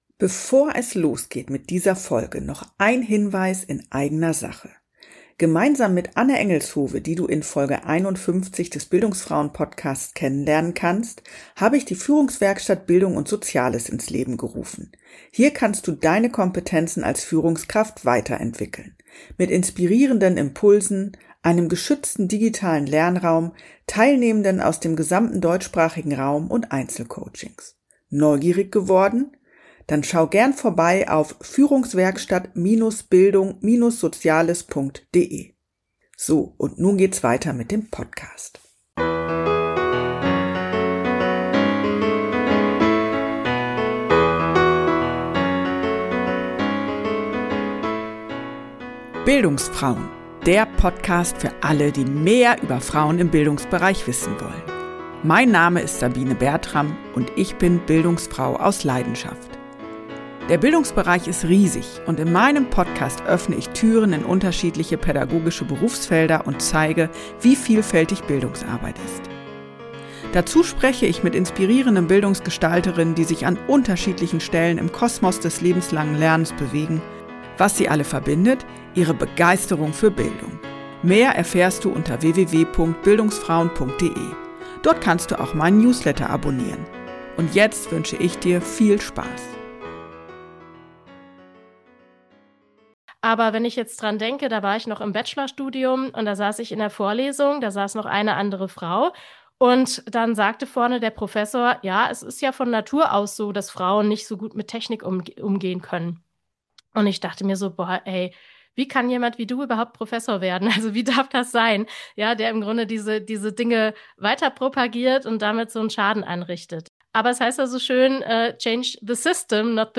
Freut euch auf ein ausführliches Gespräch